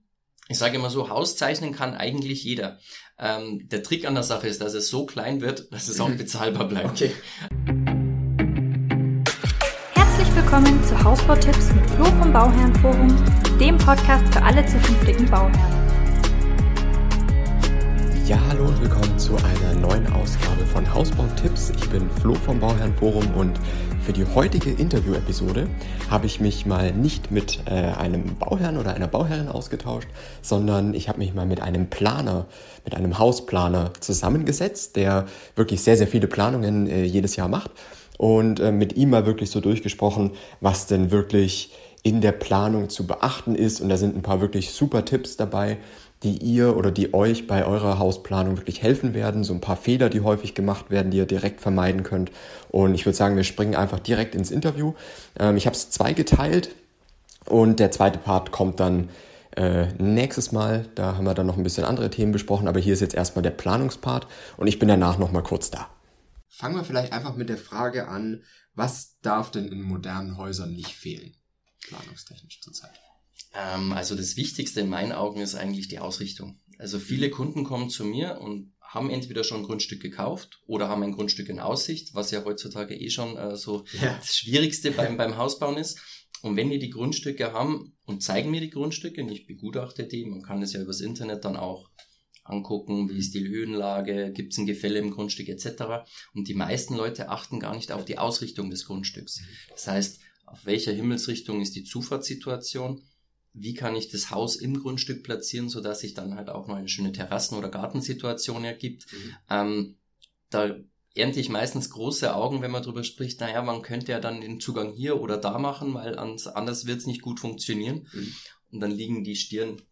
Für die heutige Interview-Episode habe ich mich mit einem Hausplaner zusammengesetzt und über Tipps zur perfekten Planung gesprochen. Ihr erfahrt, welche Fehler in der Planung oftmals gemacht werden, die am ende teuer werden und worauf es bei der Planung wirklich ankommt.